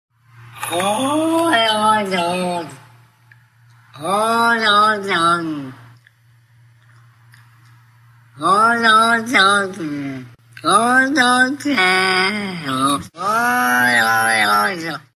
Category: Animal Ringtones